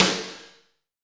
Index of /90_sSampleCDs/Roland L-CDX-01/SNR_Snares 4/SNR_Sn Modules 4
SNR FUNK 0UR.wav